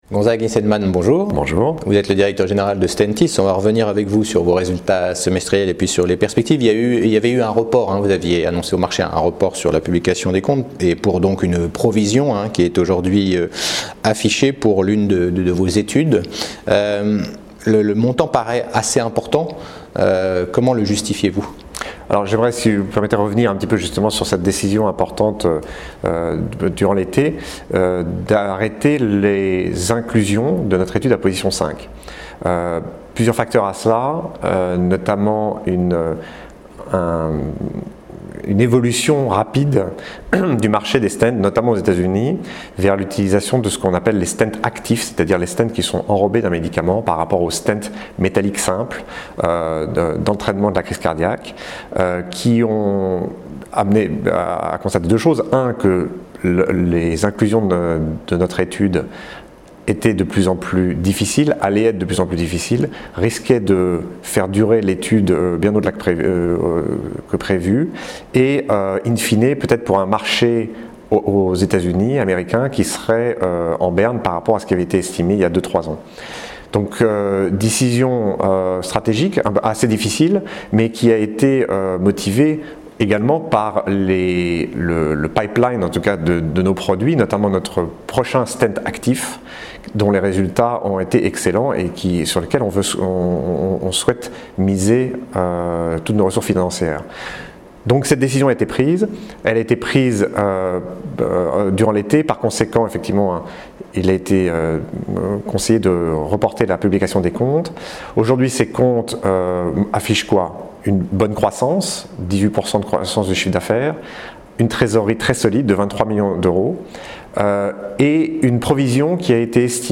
Cet interview a été tournée au Club Confair, 54 rue Laffite, 75009 Paris :